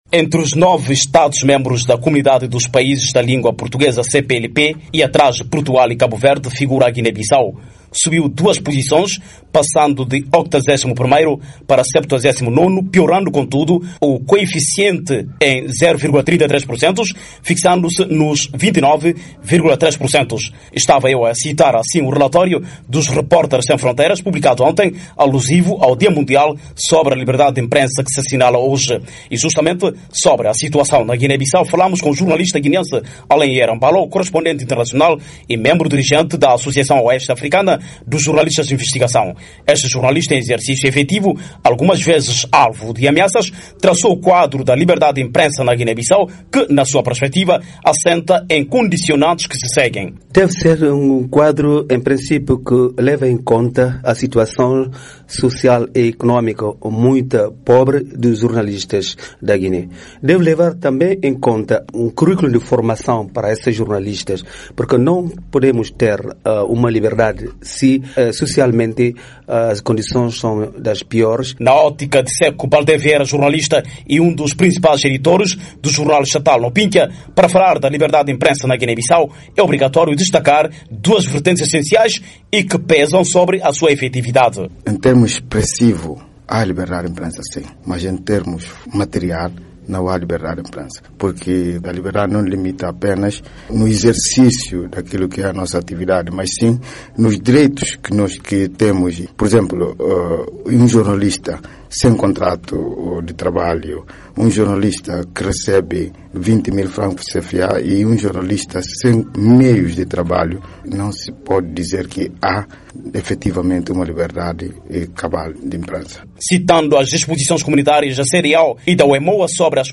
Jornalistas guineenses